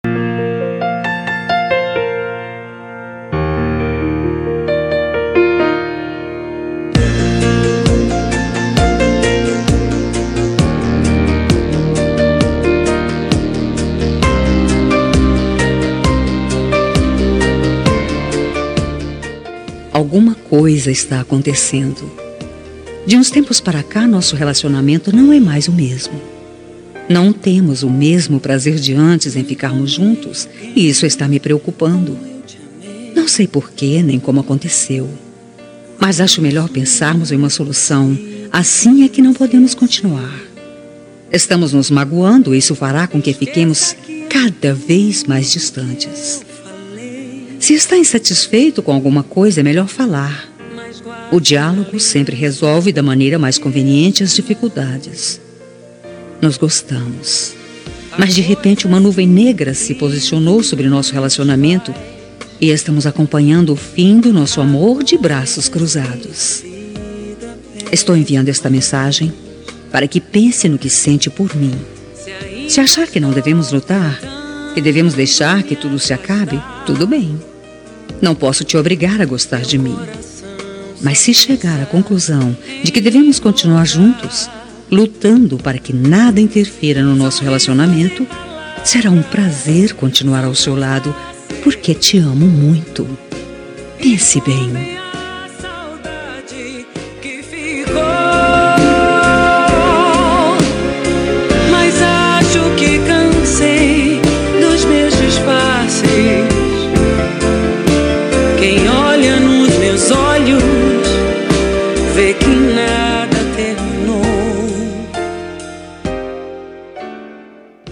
Toque para Não Terminar – Voz Feminina – Cód: 202097